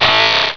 Cri de Ninjask dans Pokémon Rubis et Saphir.